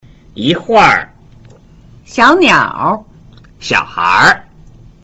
yhur, xionior, xiohir